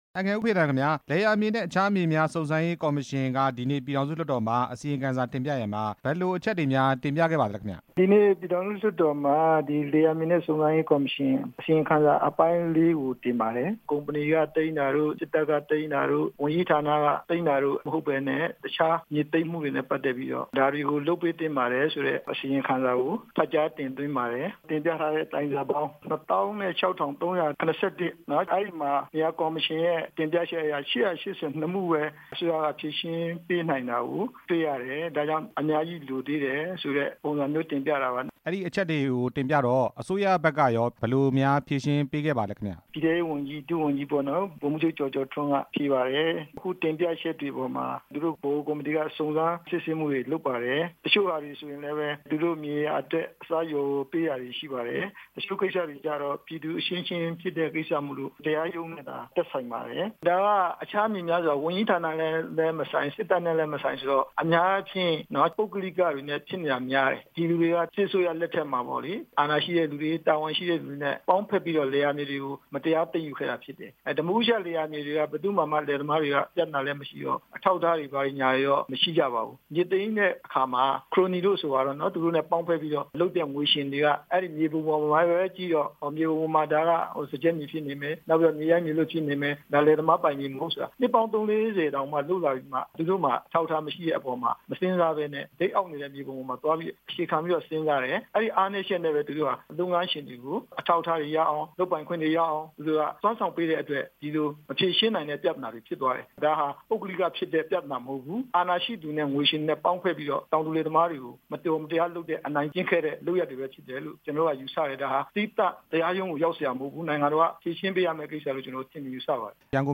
ဦးဖေသန်း နဲ့ ဆက်သွယ်မေးမြန်းချက်